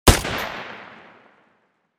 PistolShot01.wav